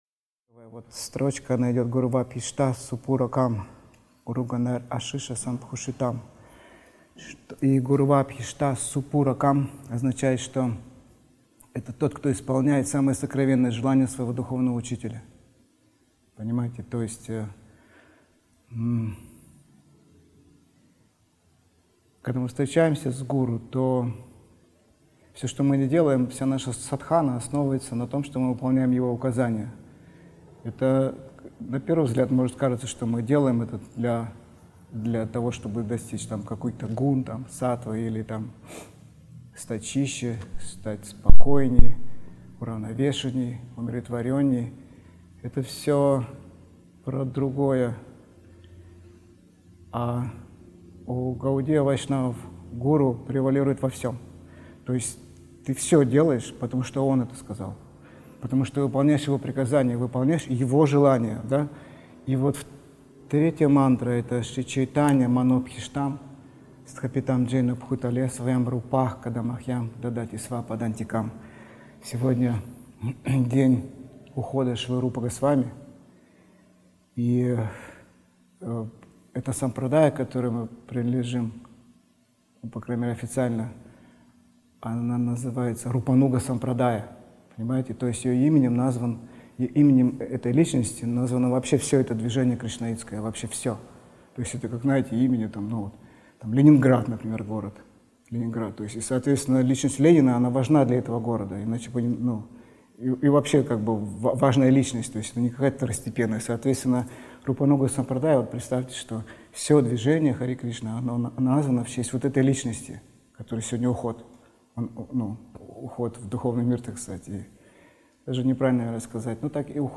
Место: Лахта (2189) Санкт-Петербург
Лекции полностью